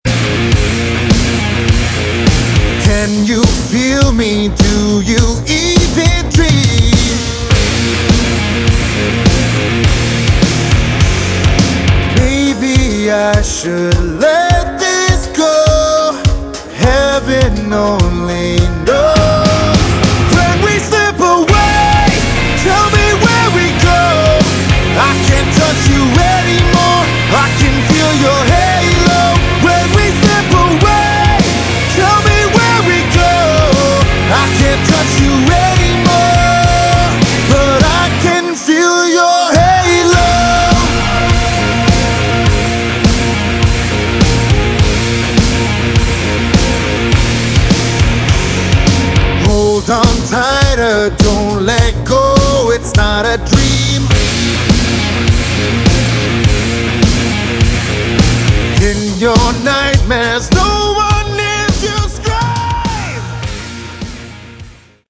Category: Modern Hard Rock
lead guitar, vocals
drums
bass